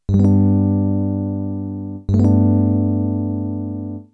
In G major you would have the left hand playing G, and the right playing GBD.
In other words, move your thumb up a note so the right hand now plays ABD.
Listen Sound Example: plain G major followed by G mu major
g_mu.wav